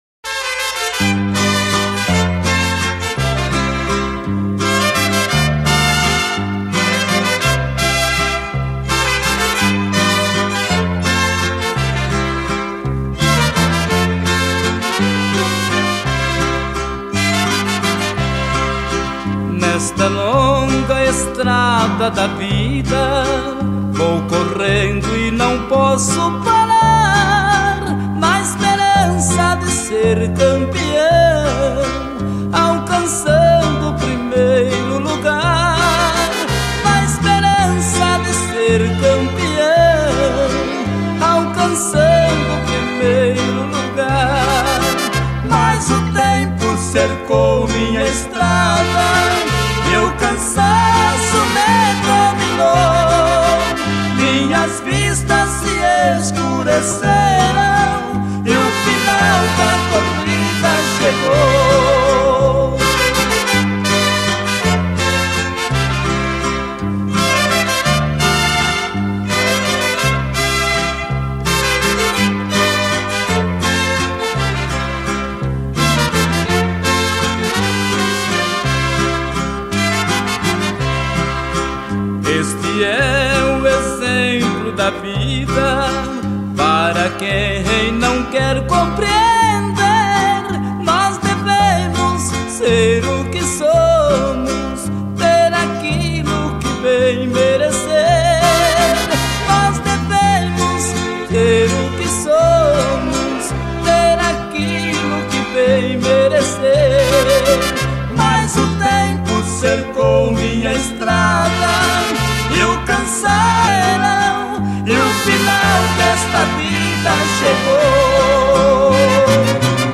2024-05-03 03:28:30 Gênero: Sertanejo Views